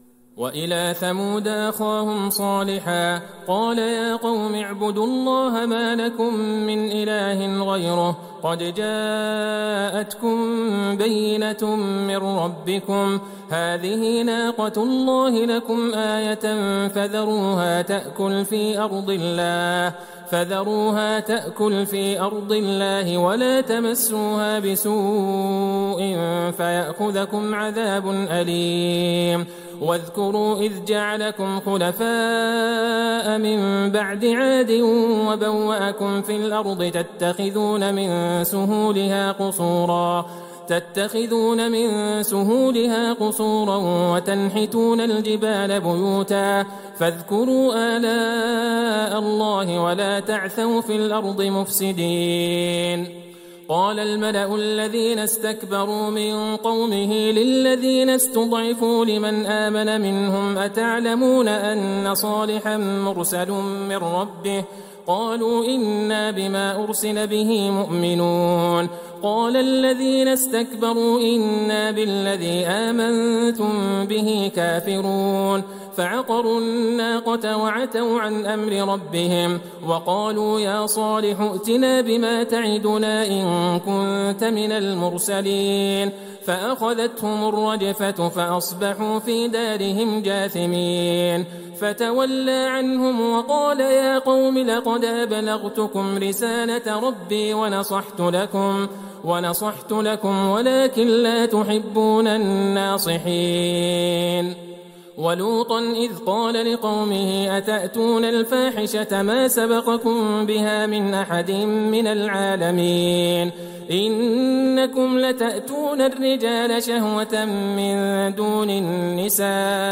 ليلة ١١ رمضان ١٤٤١هـ من سورة الأعراف { ٧٣- ١٤١ } > تراويح الحرم النبوي عام 1441 🕌 > التراويح - تلاوات الحرمين